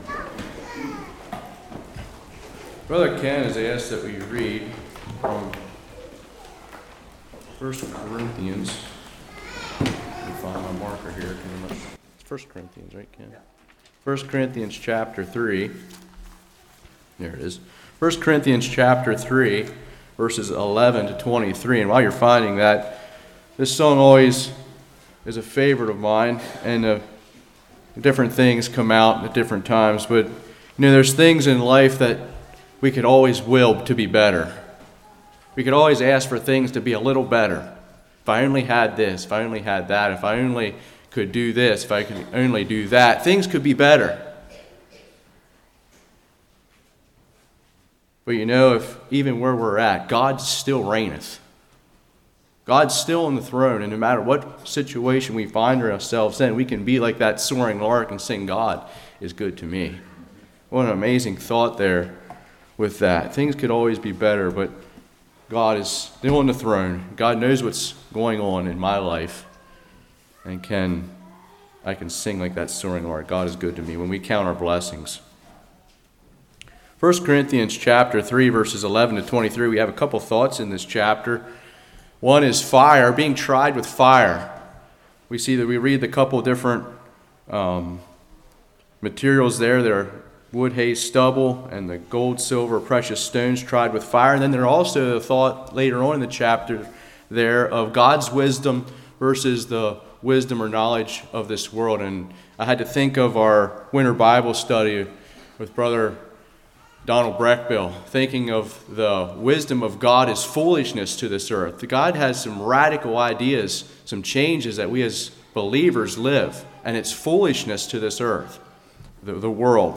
Previous Sermon: Life After Death